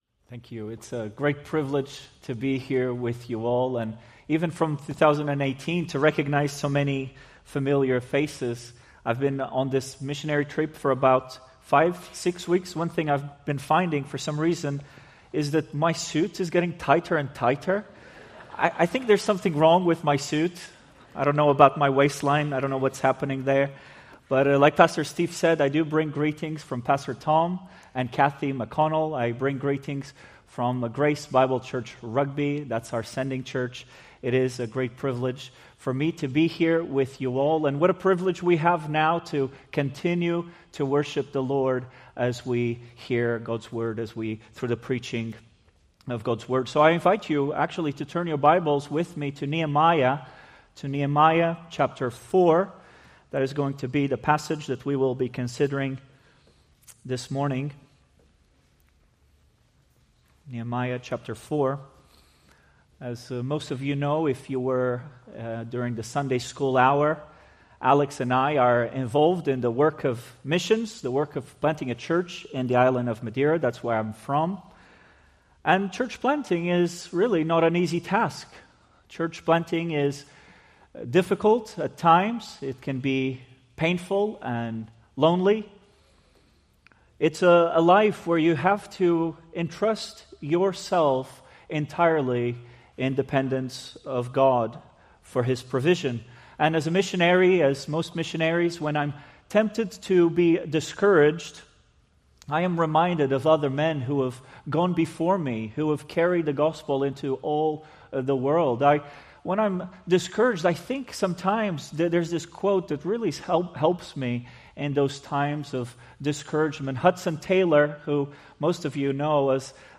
Preached March 1, 2026 from Nehemiah 4:1-6